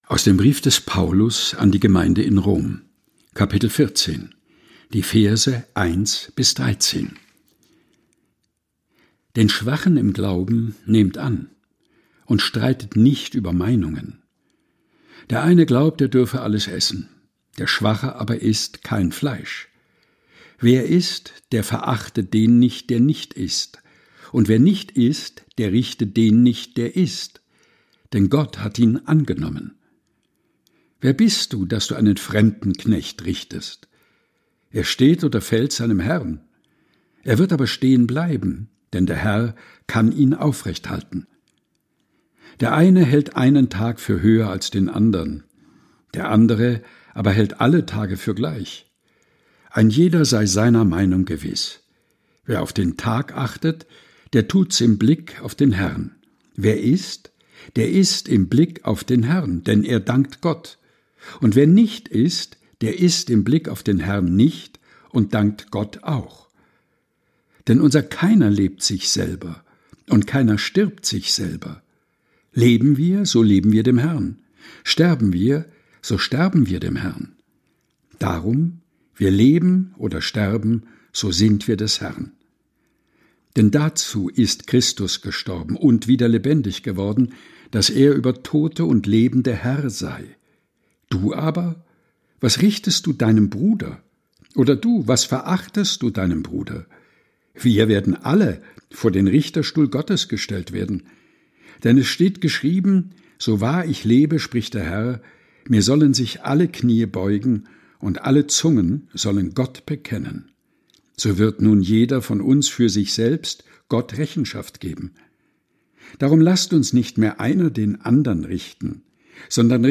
Texte zum Mutmachen und Nachdenken - vorgelesen
im heimischen Studio vorgelesen